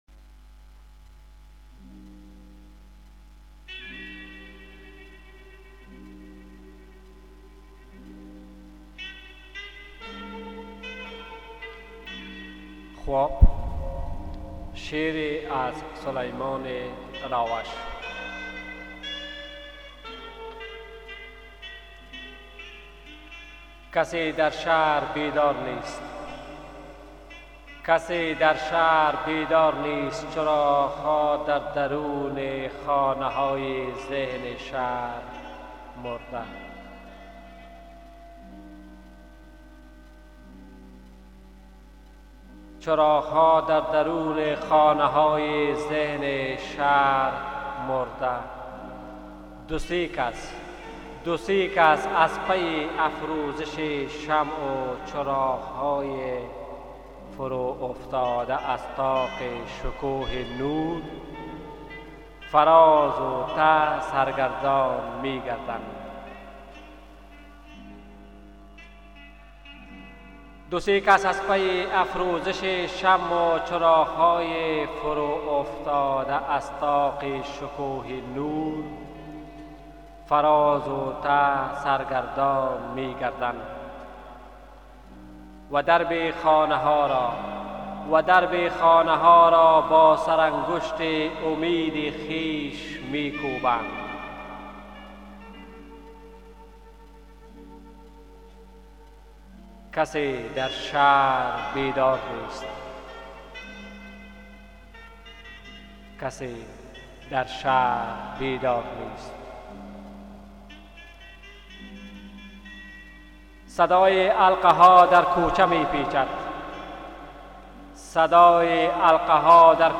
کسی در شهر بیدار نیست - شعری به خوانش